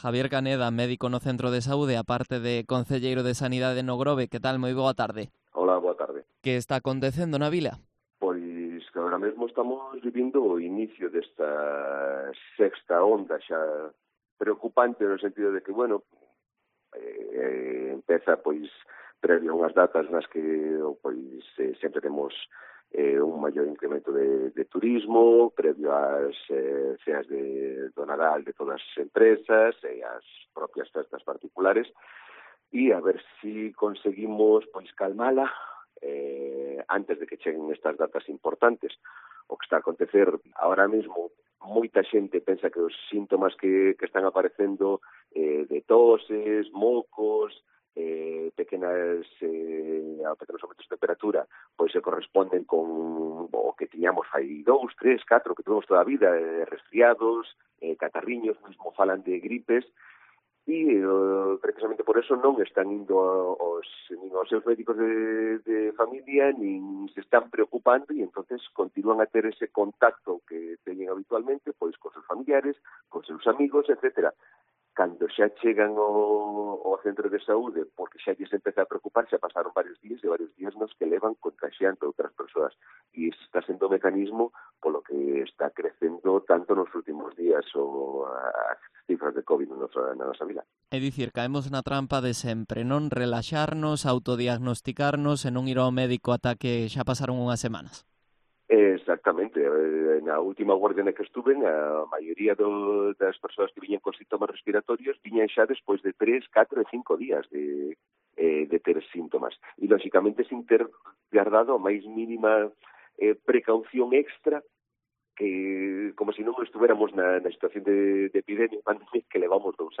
Entrevista a Javier Caneda, médico y edil de Sanidade en O Grove